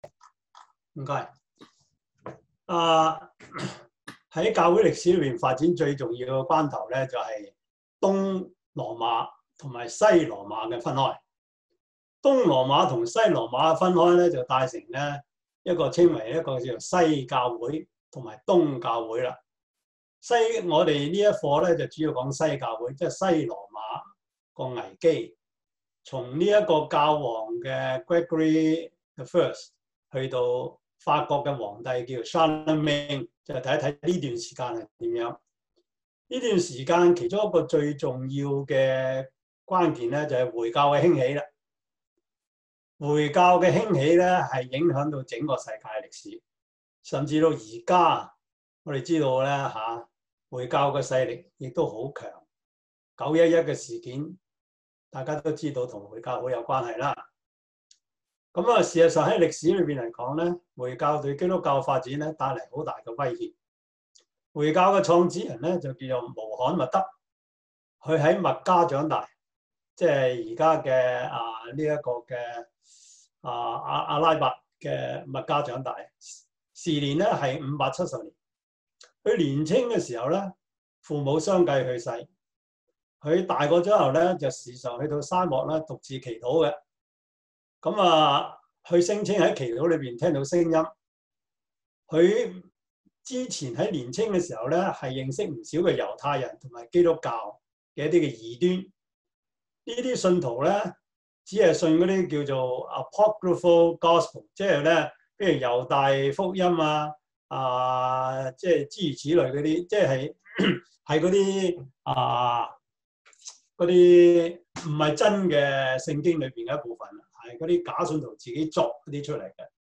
教會歷史 Service Type: 中文主日學 Preacher